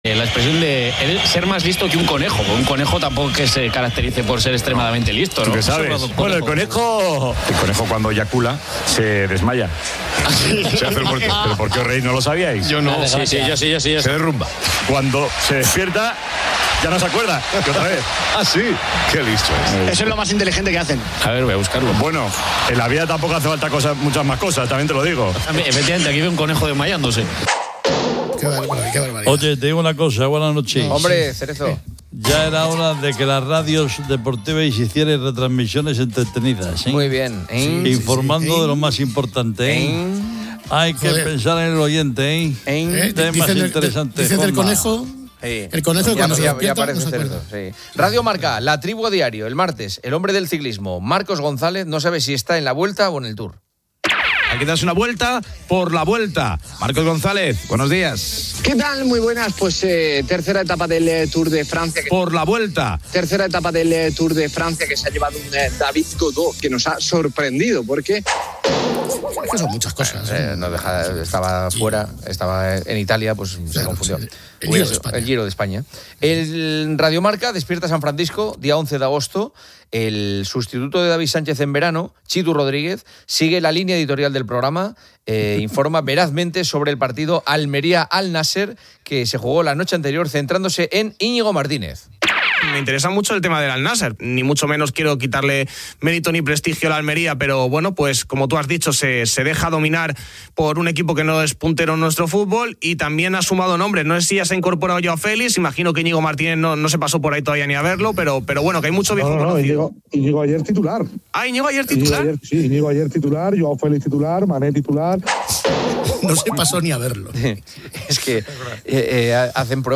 El audio presenta varios segmentos de programas de radio, incluyendo Radio Marca, El Partidazo de Cope y Poniendo las Calles. Comienza con anécdotas curiosas sobre conejos, seguidas de comentarios sobre retransmisiones deportivas y fichajes de jugadores. Se rinde un emotivo homenaje a Manolete (Manuel Esteban), recordándolo como un lector compulsivo y destacando su famosa llamada "Mister Sombrerazo".